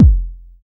909 DEEP K.wav